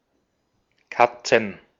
Ääntäminen
Ääntäminen : IPA: [ˈka.tsən] Tuntematon aksentti: IPA: /ˈka.ʦn̩/ IPA: /ˈka.ʦən/ Haettu sana löytyi näillä lähdekielillä: saksa Käännöksiä ei löytynyt valitulle kohdekielelle. Katzen on sanan Katze monikko.